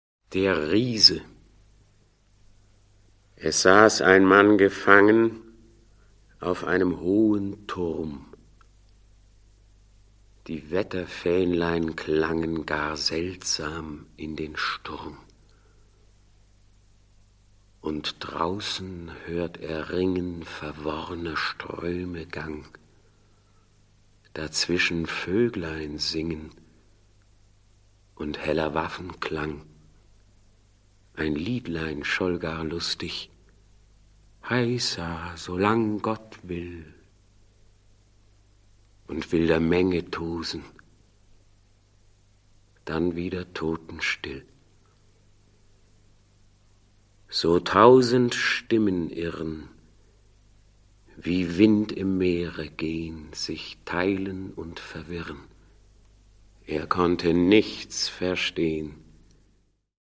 Produkttyp: Hörspiel-Download
Gelesen von: Anna Thalbach, Klausjürgen Wussow, Gerd Wameling, Peter Lühr, Mathias Wieman, Wanja Mues, Gert Westphal